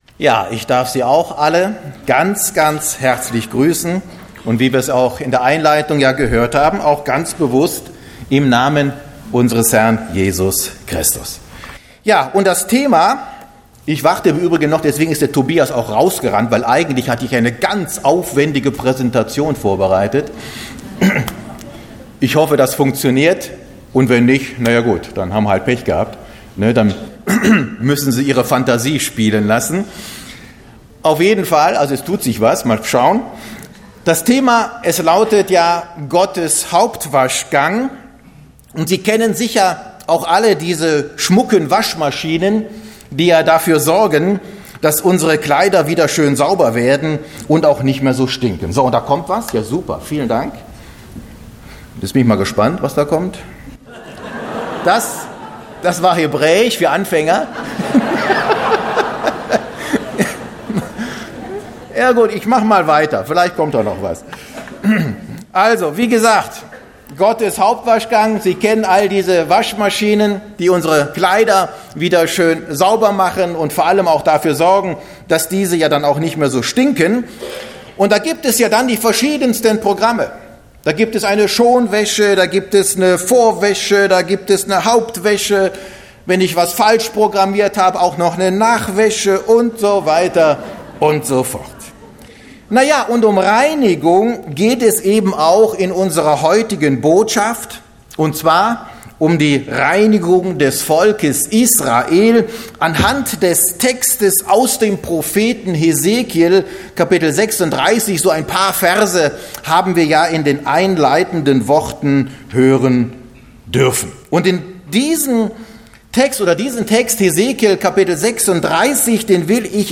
Botschaft Zionshalle https